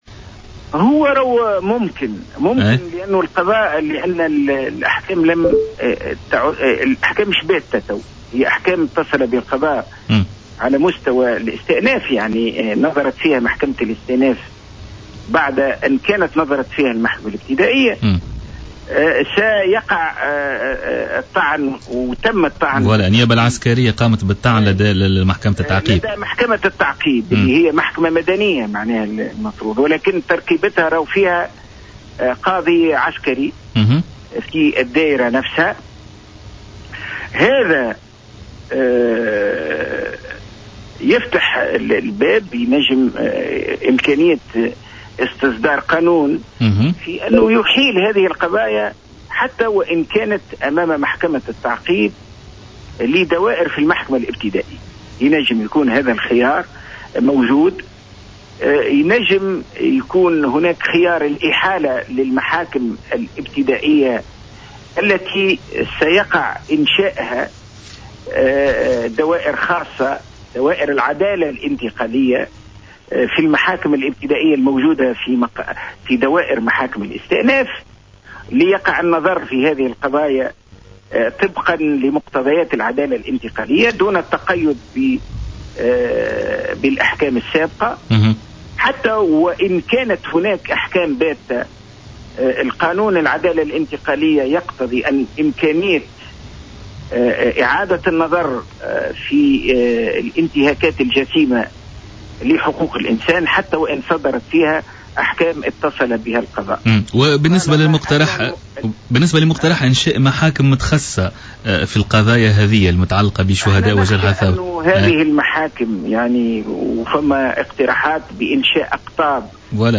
في تصريح لجوهرة أف أم اليوم خلال برنامج Politica